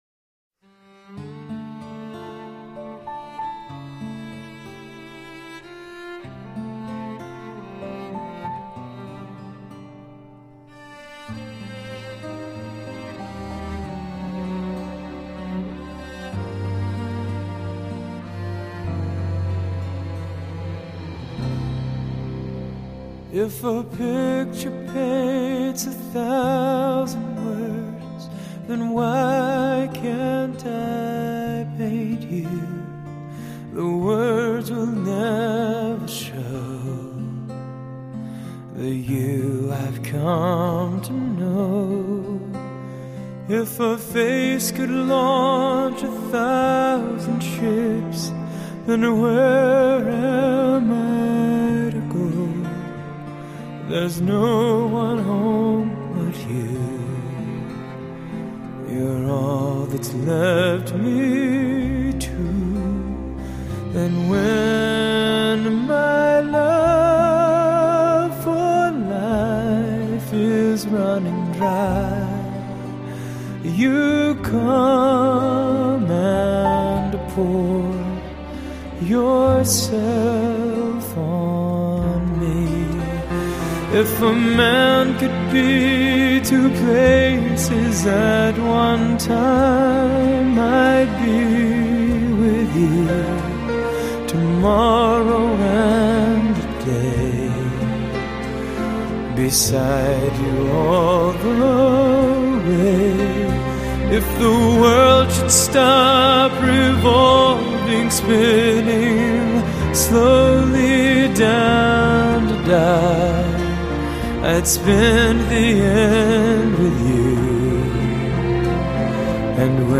Genre: Crossover